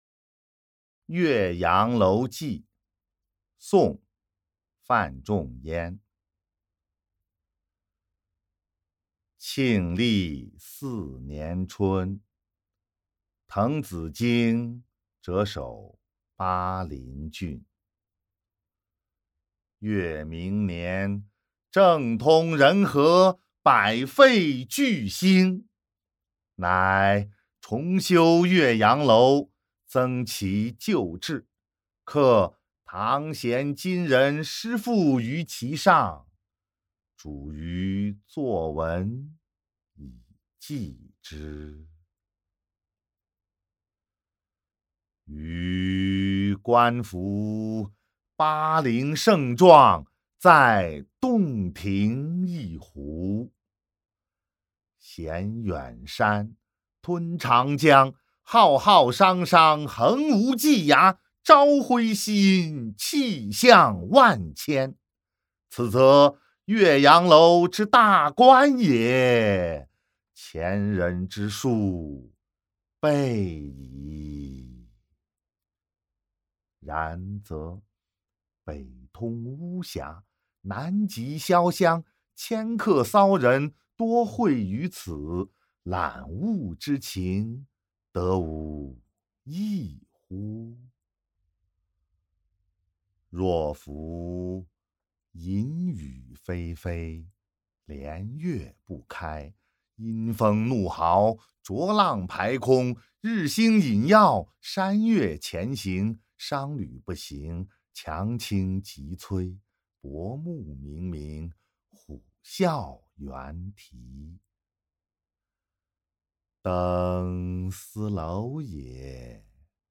［宋］范仲淹 《岳阳楼记》（读诵）